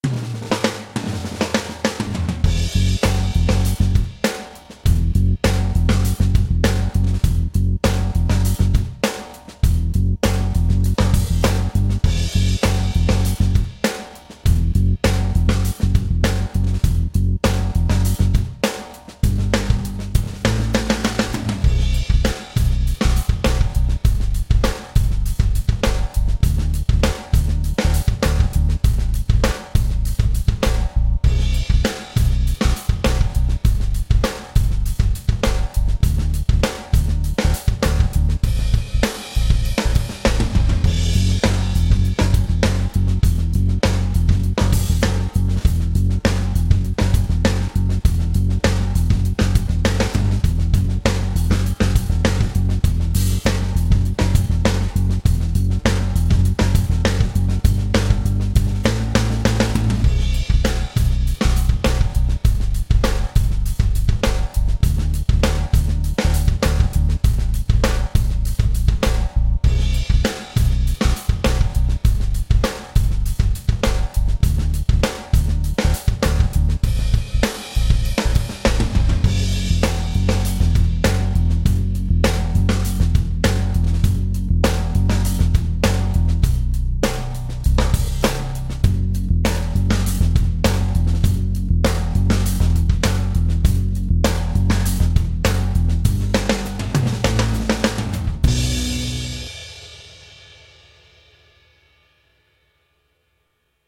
СТИЛЬ: АЛЬТЕРНАТИВА И ХЕВИ-МЕТАЛ
Чтобы сделать занятие более увлекательным, я записал так называемый "симулятор группы" — специальный трек, в котором только ударные и бас, чтобы вы могли под него тренироваться:
Здесь приведены дорожки аккомпанемента в трёх разных темпах: 100, 110 и 120 ударов в минуту:
100 BPM
metal-song-minus-one-track-100bpm.mp3